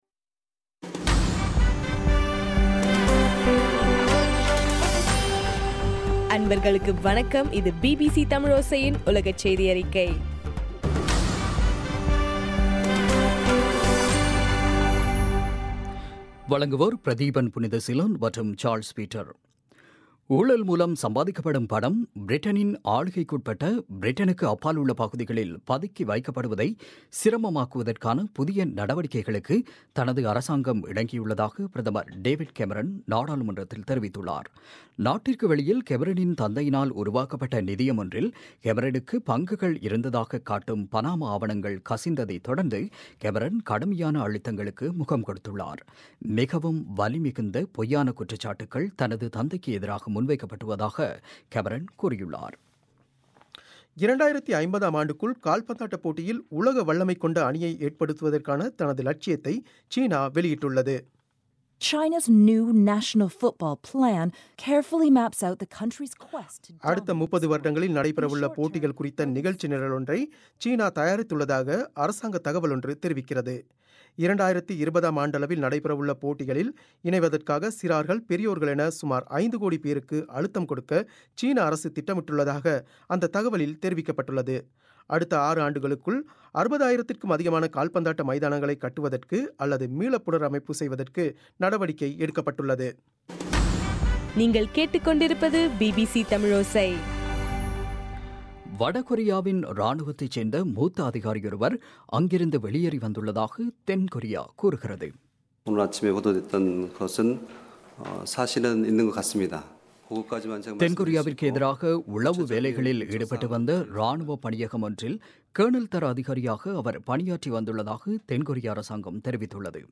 11 ஏப்ரல் 2016 பிபிசி செய்தியறிக்கை